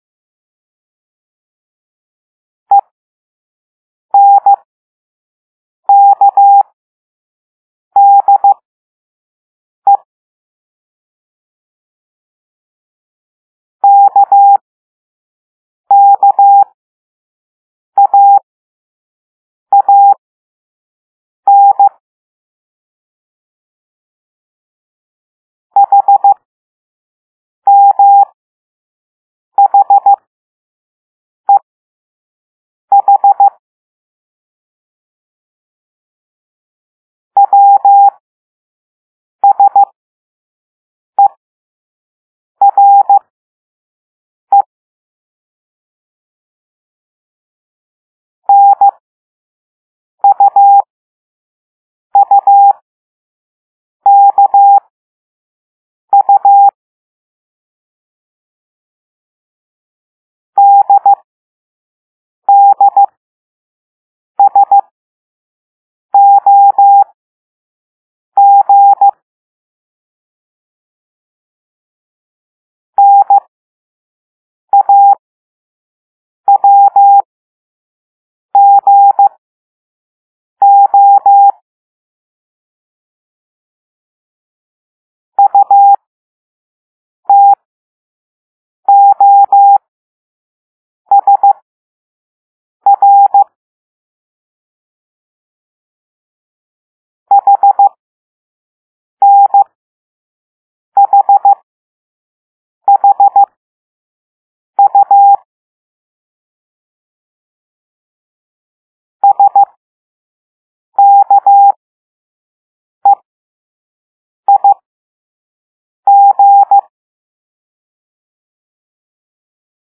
Morse Code Lesson 5
W - "dit dah dah"
U - "dit dit dah"
H - "dit dit dit dit"